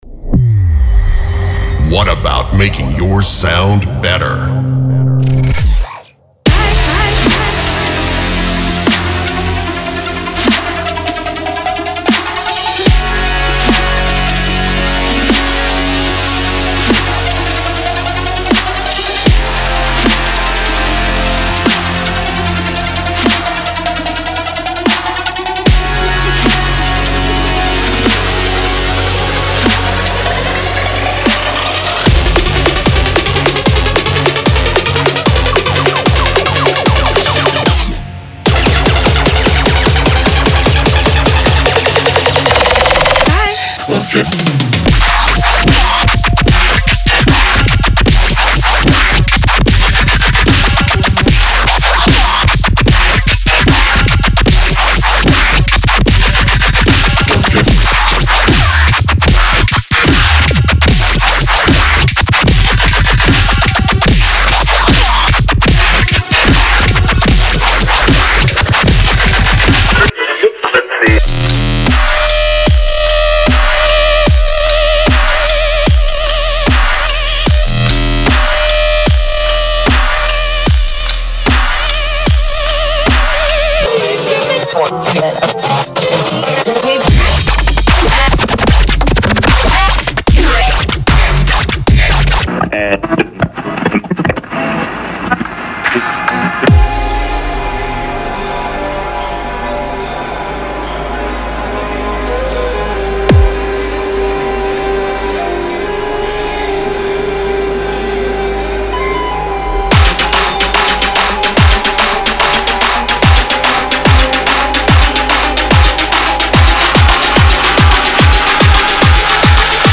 低音，鼓，打击乐器和和声环以融洽的平衡融合在一起。
使用尖锐而脆脆的琴弦，或用电影交响乐团的刺弦强调强度。